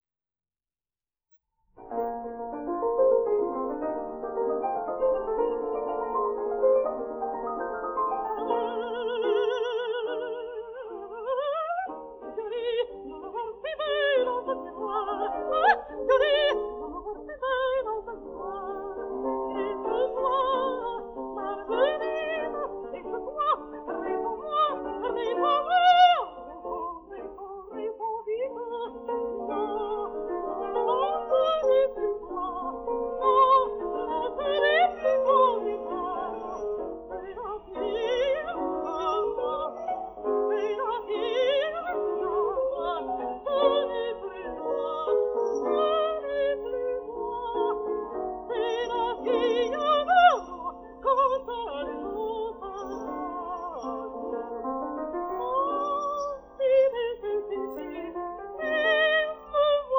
denoised_cropped.wav